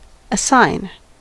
assign: Wikimedia Commons US English Pronunciations
En-us-assign.WAV